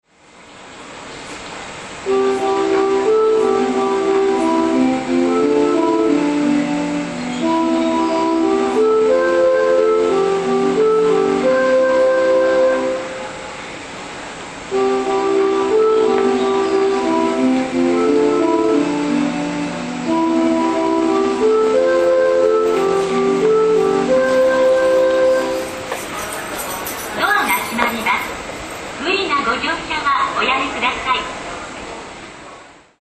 全ホーム旋律は同じですがアレンジが異なります。